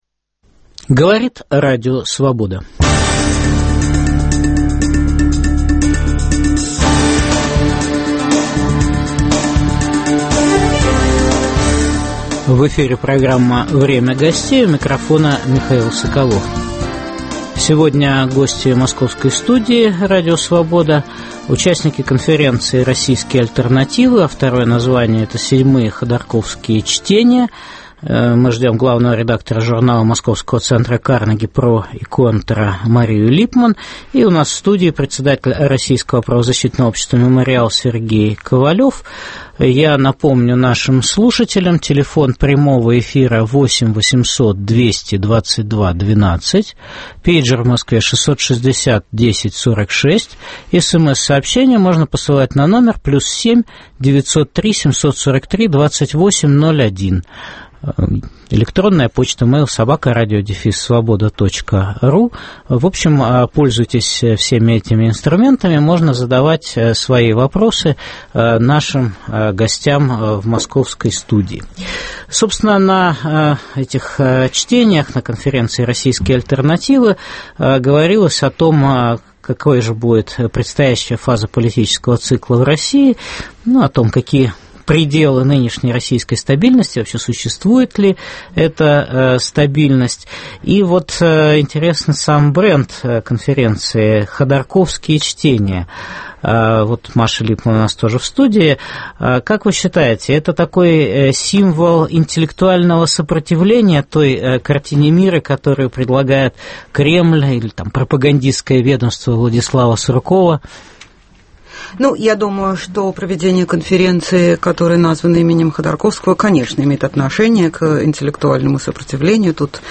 Какой будет предстоящая фаза политического цикла в России? Каковы пределы стабильности? Гости московской студии Радио Свобода